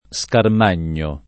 Scarmagno [ S karm # n’n’o ]